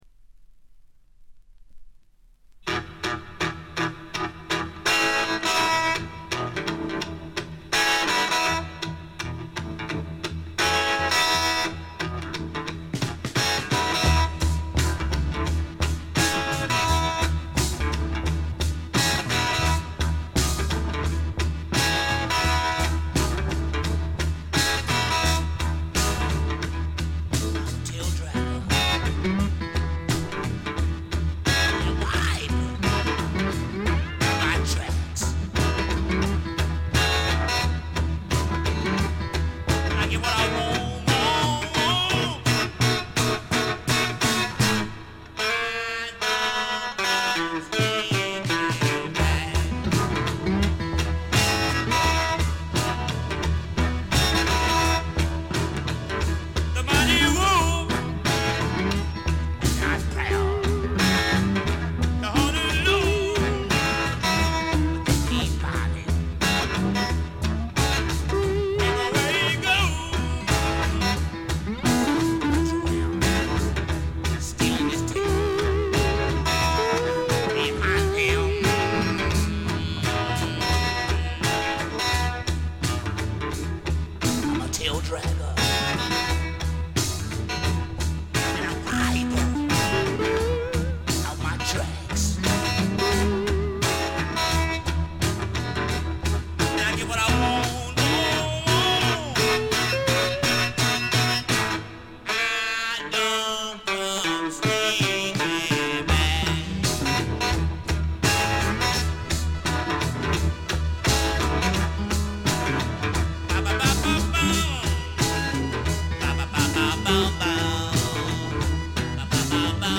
最小限のバック編成と搾り出すようなヴォーカルが織り成す、ねばつくような蒸し暑いサウンド。
試聴曲は現品からの取り込み音源です。